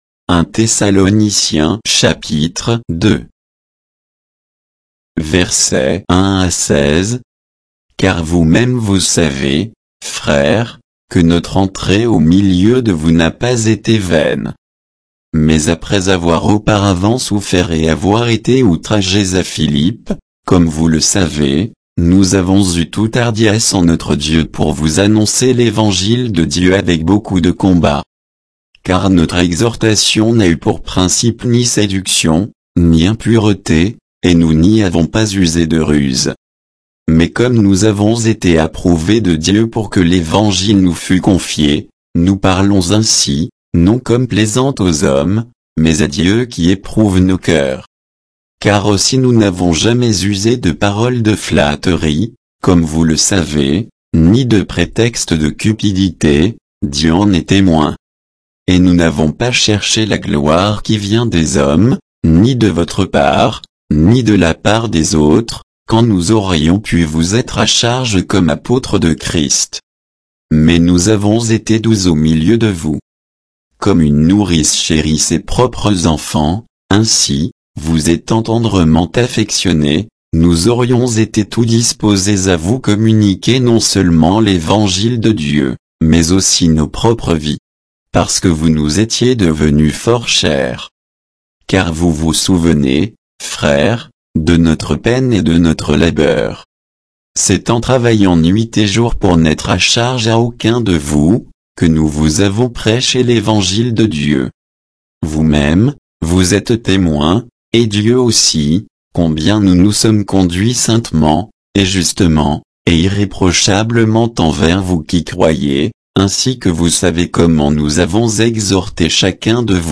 Bible_1_Thessaloniciens_2_(sans_notes,_avec_indications_de_versets).mp3